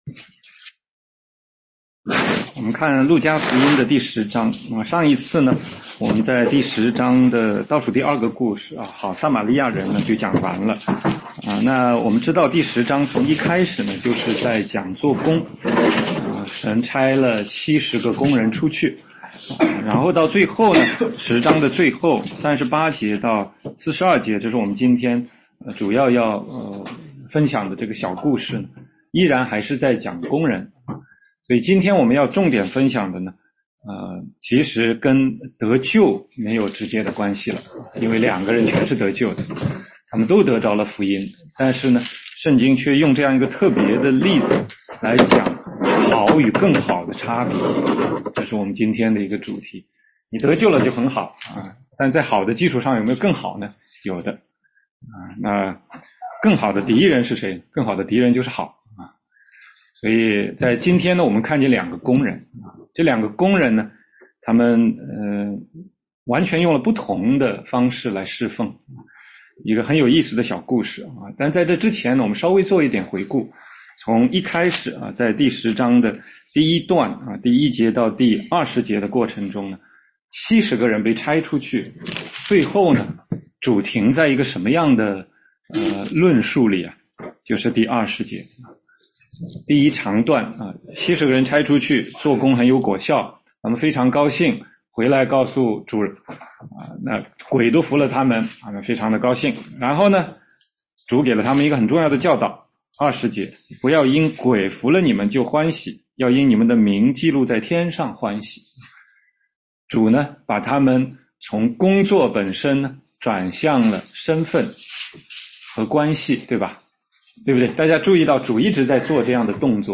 16街讲道录音 - 路加福音10章38-42节：马大和马利亚